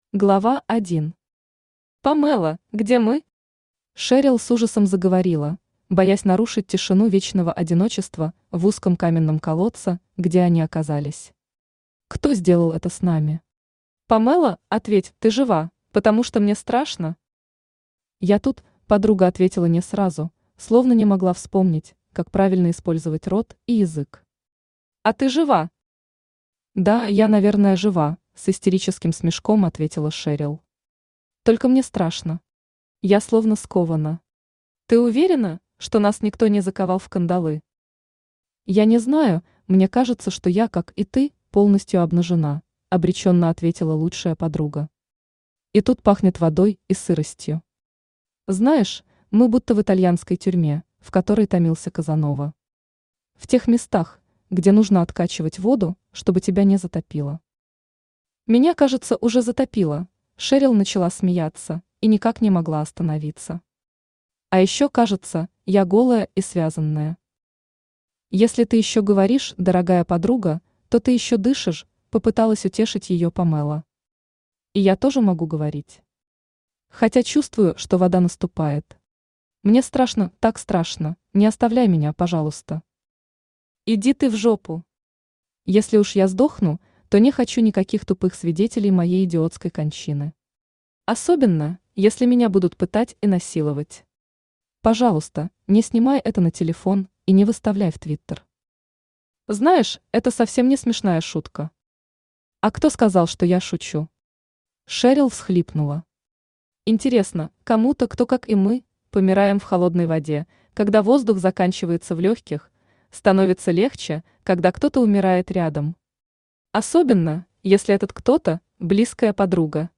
Аудиокнига В ловушке | Библиотека аудиокниг
Aудиокнига В ловушке Автор Кристина Воронова Читает аудиокнигу Авточтец ЛитРес.